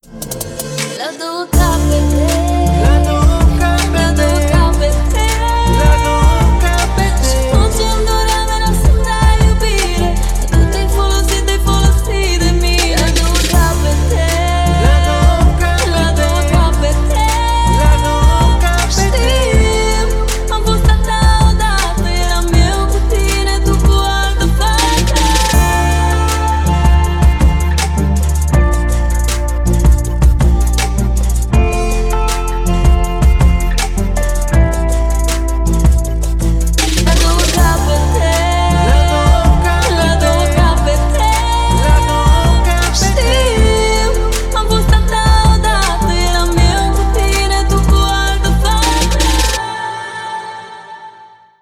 красивые
женский вокал
dance
club